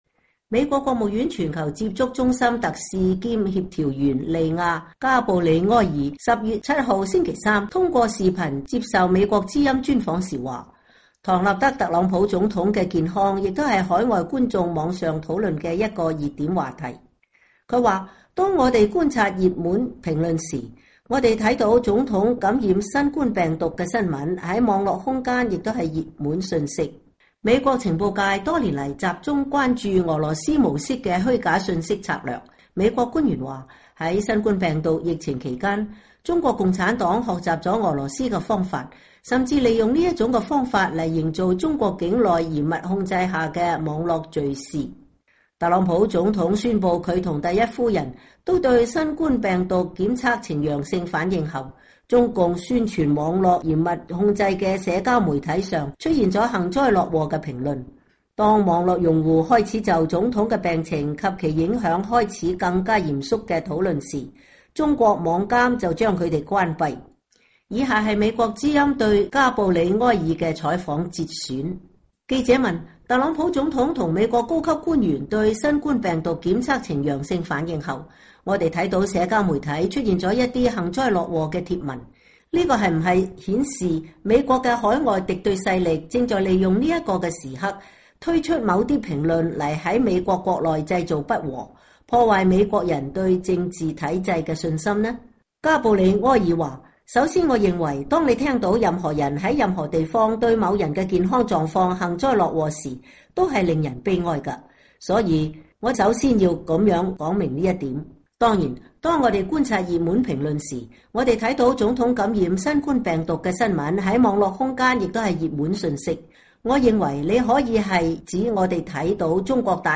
專訪美國務院官員- 外國對手散佈有關疫情和選舉的虛假信息